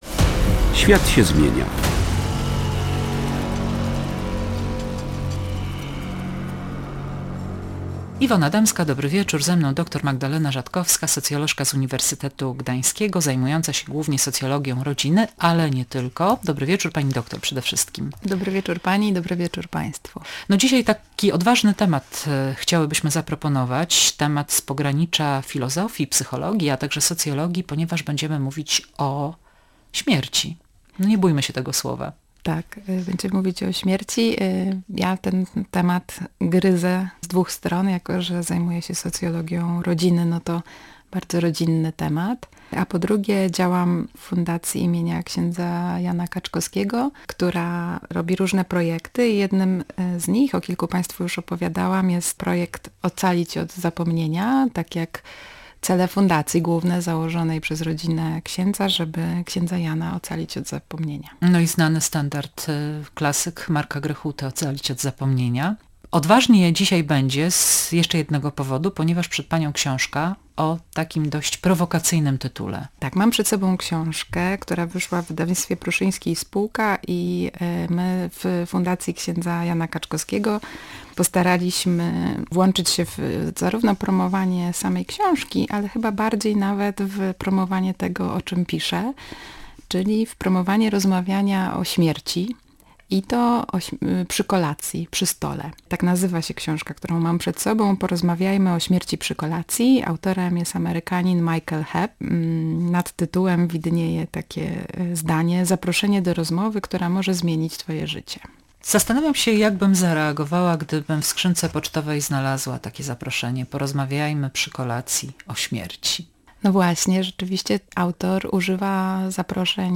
Pxhere) /audio/dok3/swiatsiezmienia291019.mp3 Tagi: audycje Radia Gdańsk , świat się zmienia